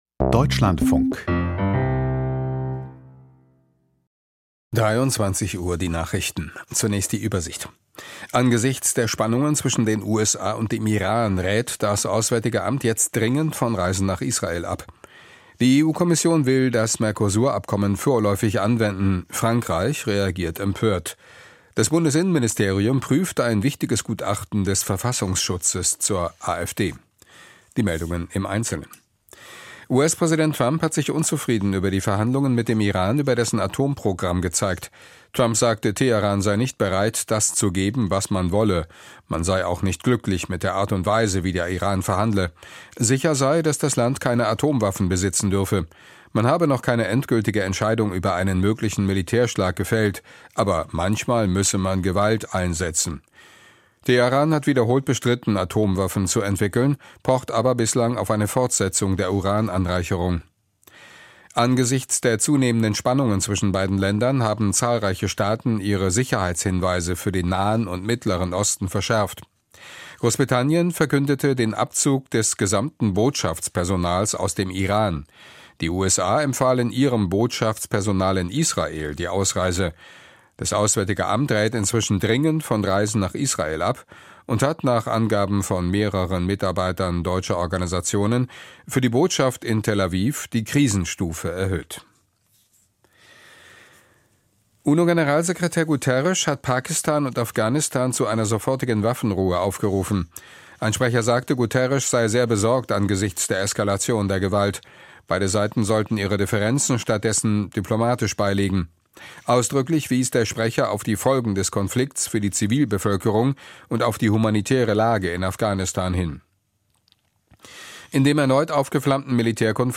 Die Nachrichten vom 27.02.2026, 23:00 Uhr
Aus der Deutschlandfunk-Nachrichtenredaktion.